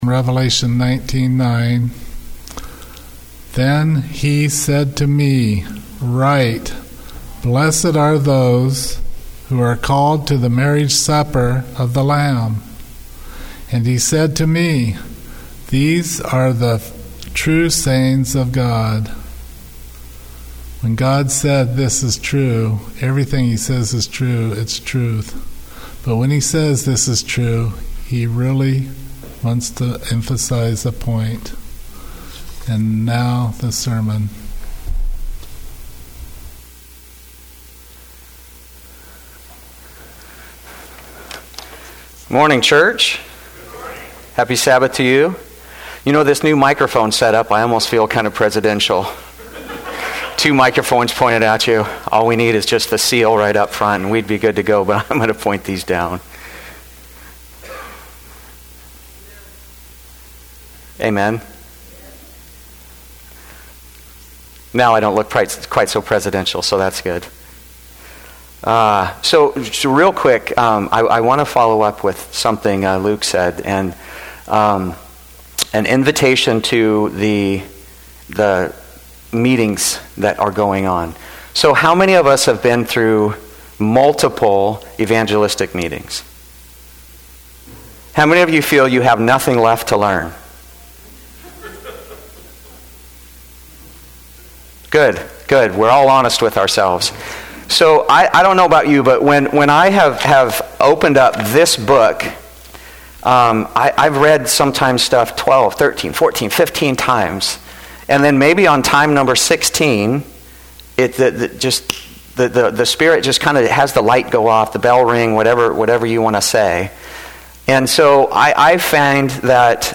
Sermons and Talks 2025